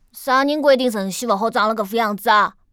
c01_3残疾小孩_3.wav